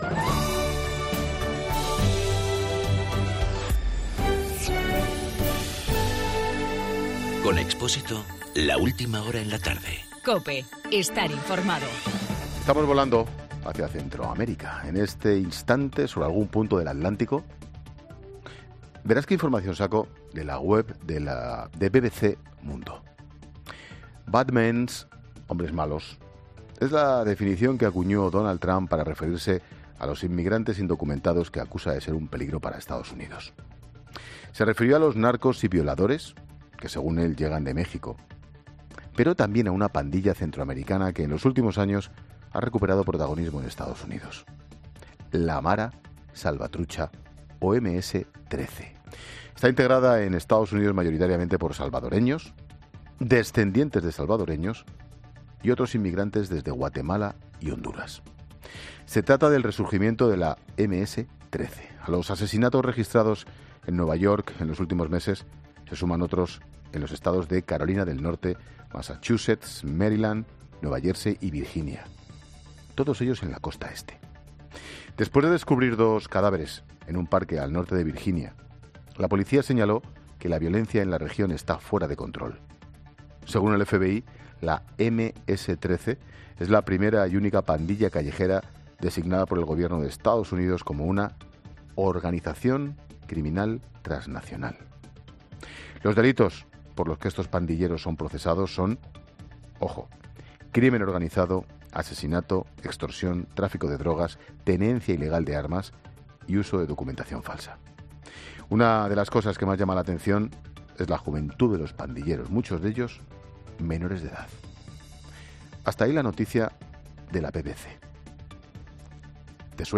AUDIO: El comentario de Ángel Expósito de camino a Guatemala.